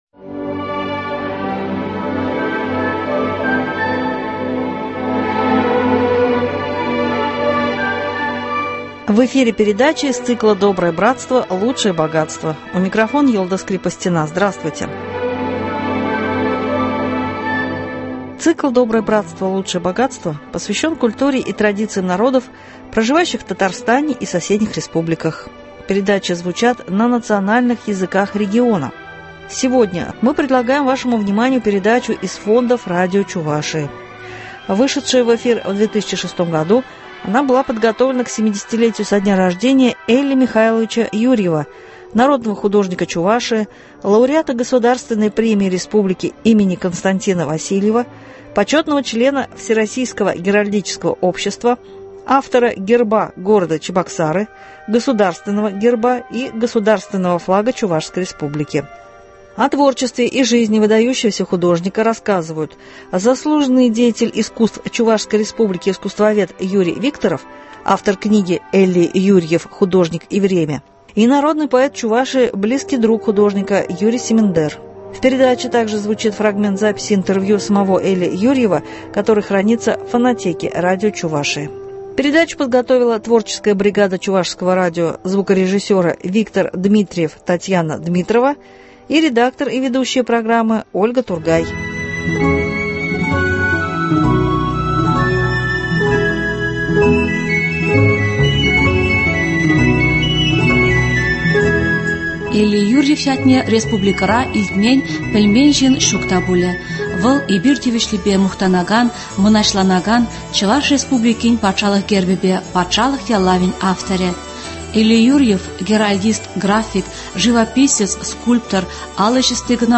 Цикл посвящен культуре и традициям народов, проживающих в Татарстане и соседних республиках, передачи звучат на национальных языках региона . Сегодня мы предлагаем вашему вниманию передачу из фондов радио Чувашии.